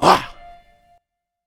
Southside Vox.wav